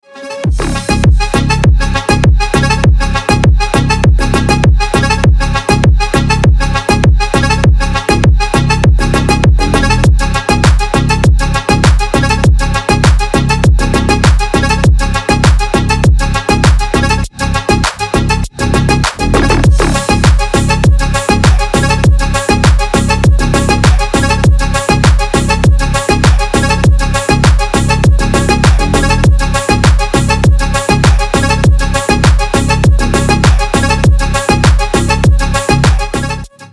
Клубная мелодия
Клубные рингтоны